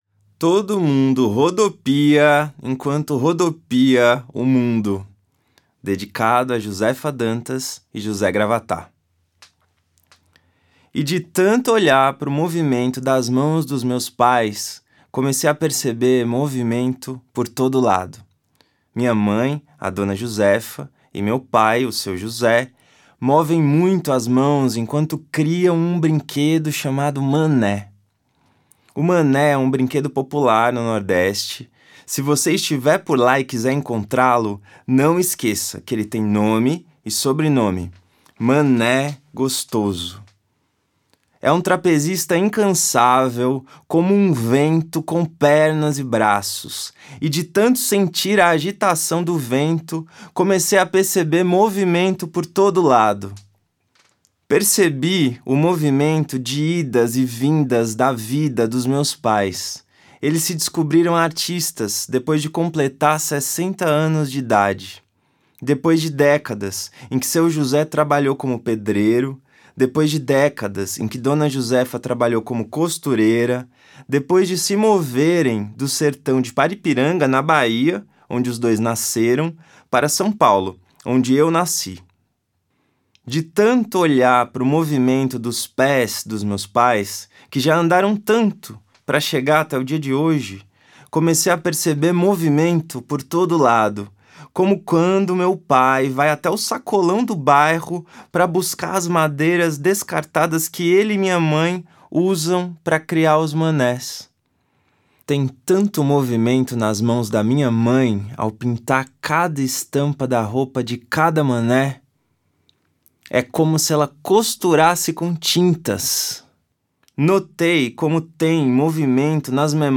Rodopia-somente-voz.mp3